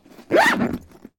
backpack_close.ogg